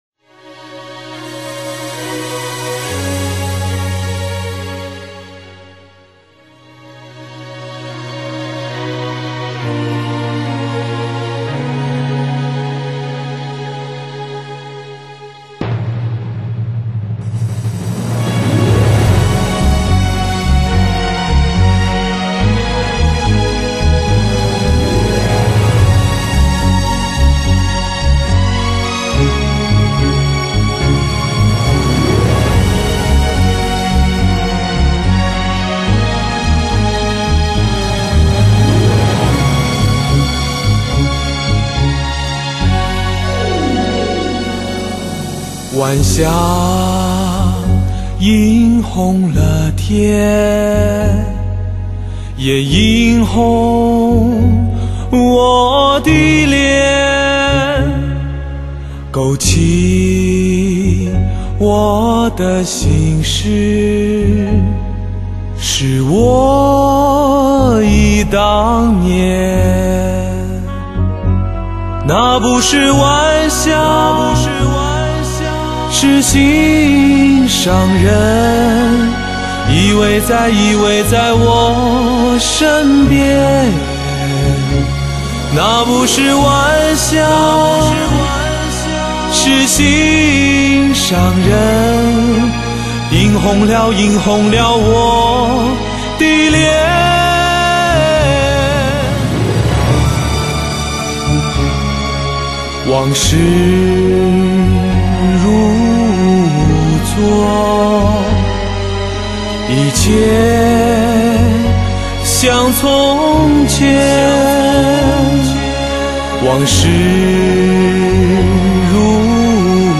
穿透灵魂深处的绝美歌声
纯纯之音,悠悠的情感,曾经熟悉的旋律,仿佛又回响在耳边,再度引发您心底的共鸣!!